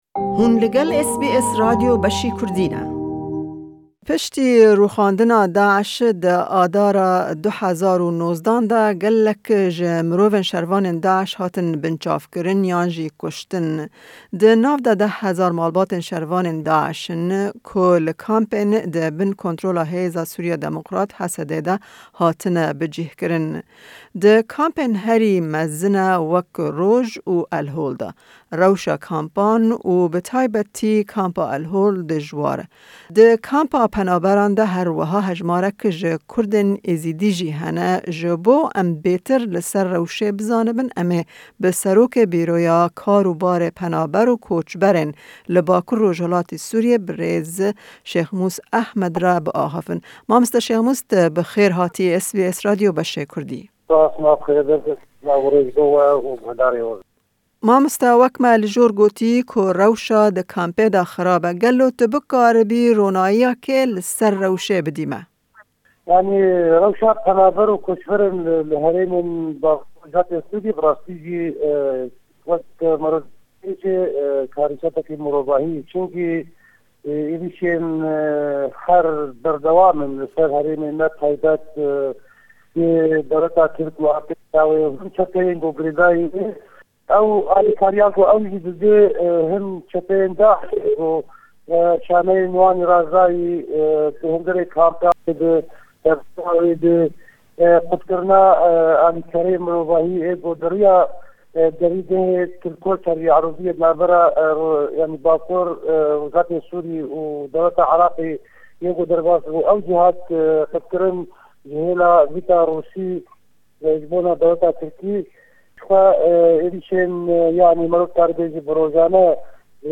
Hevpeyvîneke taybet derbarî rewşa kampên penaberan yên Al-Hol û Roj de bi serokê Bîroya Kar û Barê Penaber û Koçberên li bakur/rojhilatî Sûriyê berêz Şêxmûs Ehmed re.